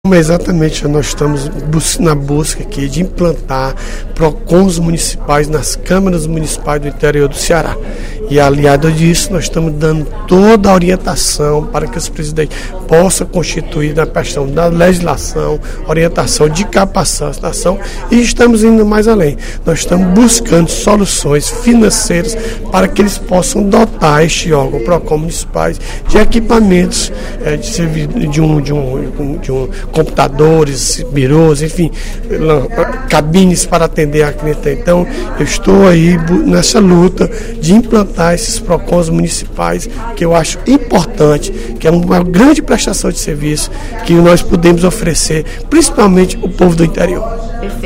O deputado Odilon Aguiar (Pros) anunciou, durante o primeiro expediente da sessão plenária desta quinta-feira (25/06), que está fechando uma parceria com o procurador geral de Justiça, Ricardo Machado, para a instalação de Procons em cada uma das câmaras municipais do Estado.